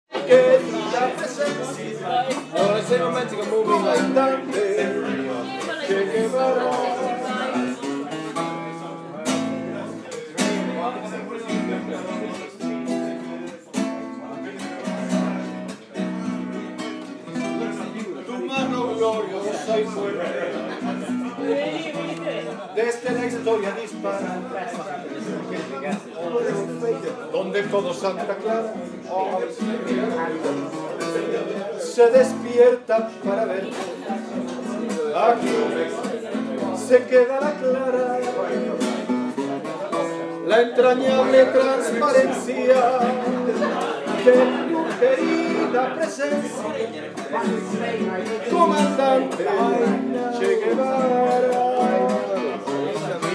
Argentinian Steakhouse